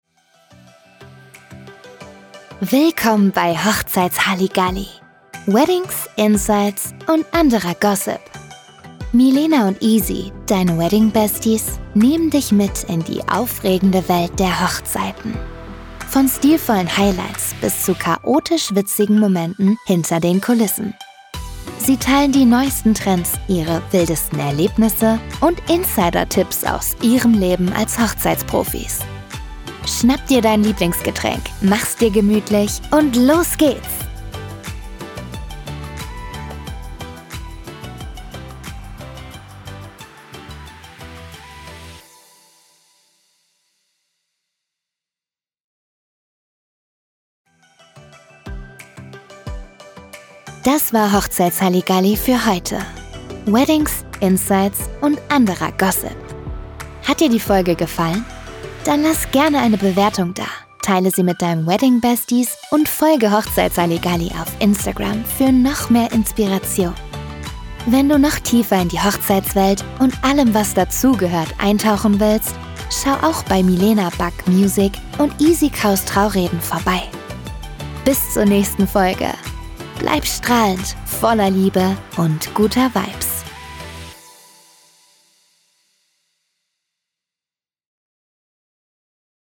Sprecherin für VoiceOver, Synchron & Hörbücher
In meinem Homestudio setze ich deine Idee in hochwertige Sprachaufnahmen um.
Demoaufnahme-Podcasting.mp3